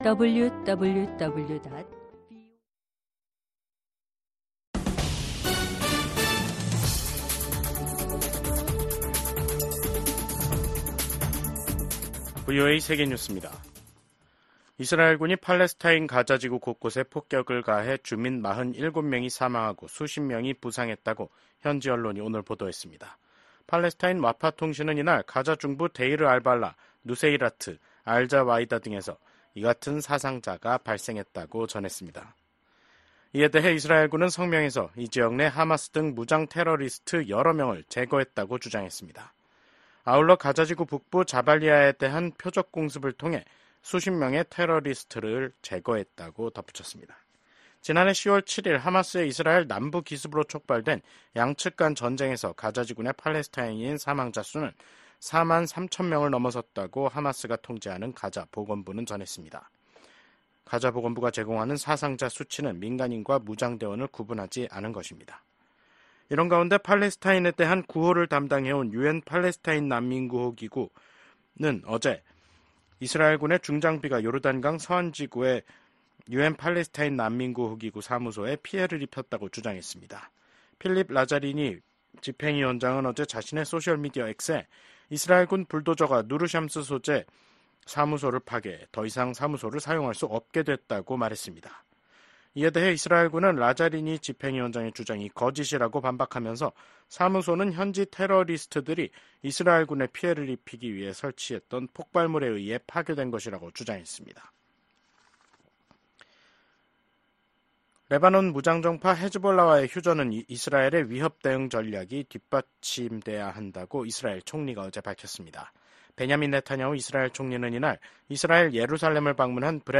VOA 한국어 간판 뉴스 프로그램 '뉴스 투데이', 2024년 11월 1일 2부 방송입니다. 북한은 신형 대륙간탄도미사일(ICBM) ‘화성-19형’을 시험발사했고 이 ICBM이 ‘최종완결판’이라고 주장했습니다. 러시아에 파병된 북한군이 곧 전투에 투입될 것으로 예상된다고 미국 국무·국방장관이 밝혔습니다. 북한군 8천 명이 우크라이나와 가까운 러시아 쿠르스크 지역에 있다는 정보를 입수했다고 미국 유엔 차석대사가 밝혔습니다.